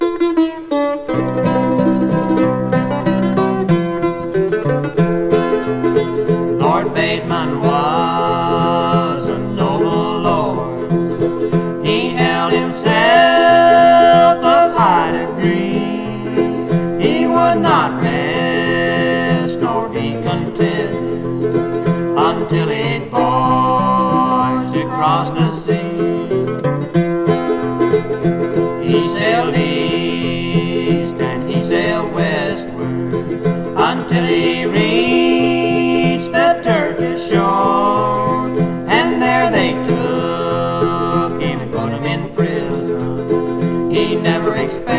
Folk, Bluegrass　USA　12inchレコード　33rpm　Mono